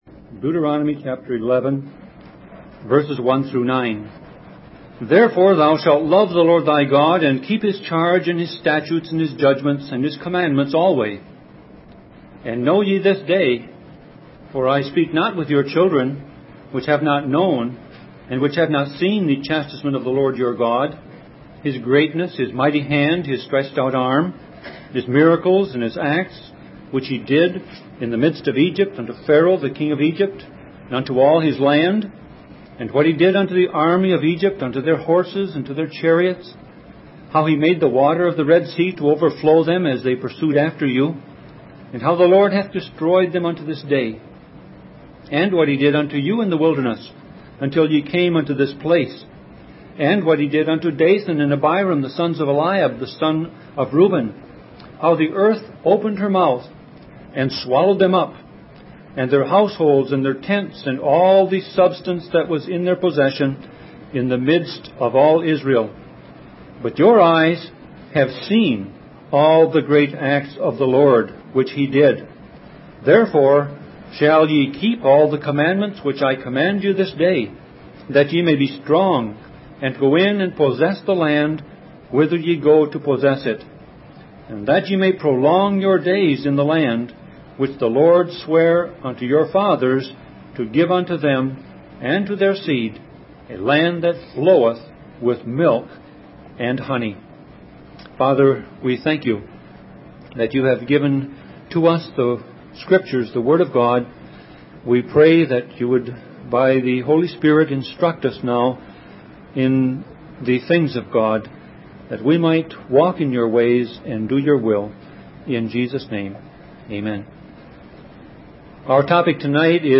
Series: Sermon Audio Passage: Deuteronomy 11:1-9 Service Type